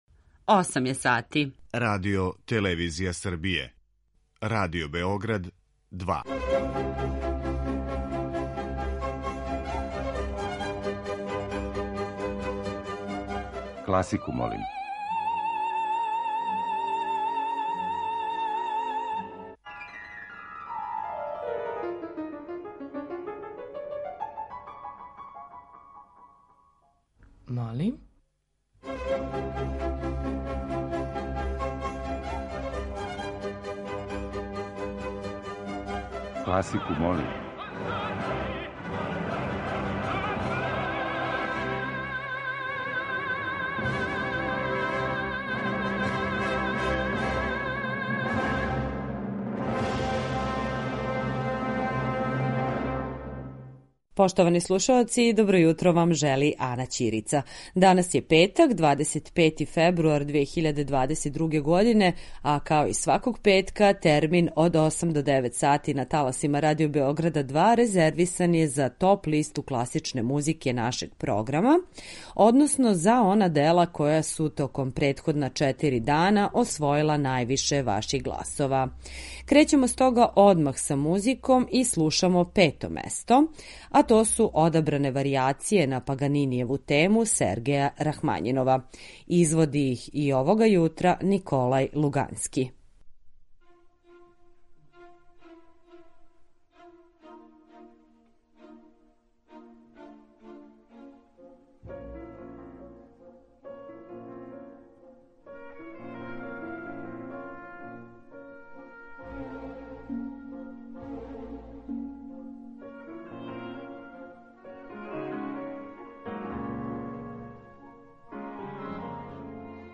klasika.mp3